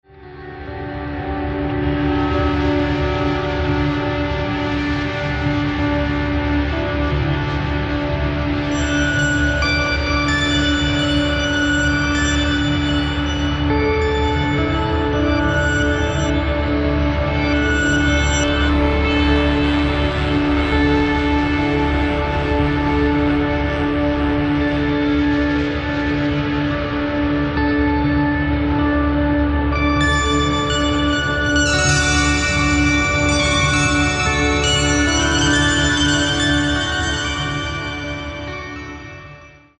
full of guitars and is very calm